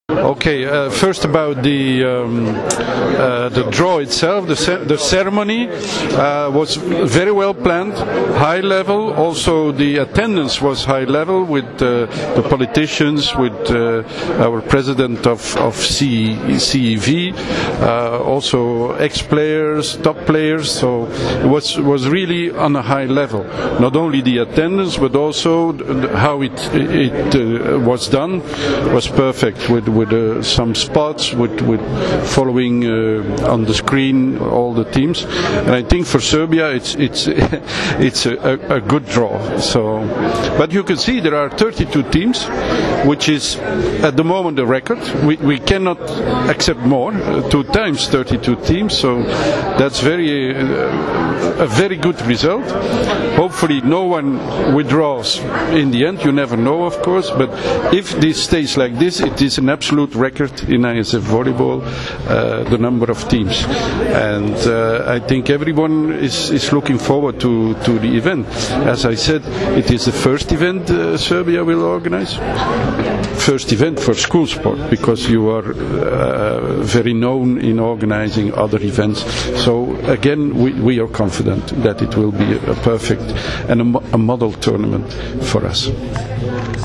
U Palati Srbija danas je obavljen žreb za Svetsko školsko prvenstvo u odbojci, koje će se odigrati od 25. juna – 3. jula u Beogradu.
IZJAVA